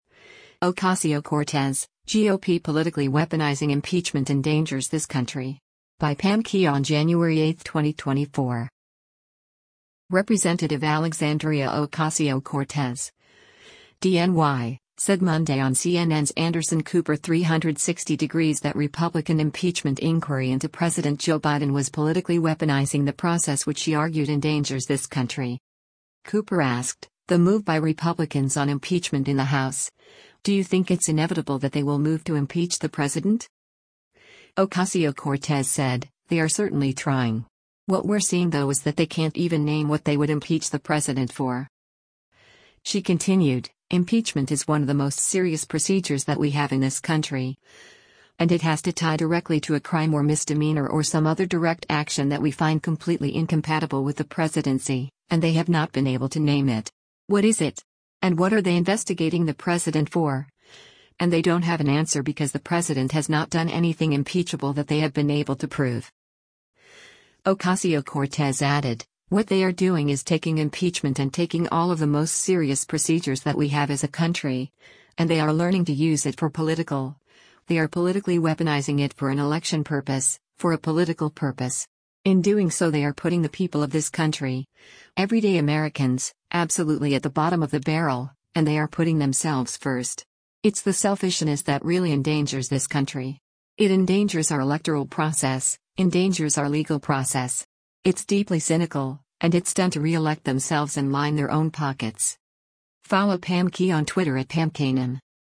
Representative Alexandria Ocasio-Cortez (D-NY) said Monday on CNN’s “Anderson Cooper 360°” that Republican impeachment inquiry into President Joe Biden was “politically weaponizing” the process which she argued “endangers this country.”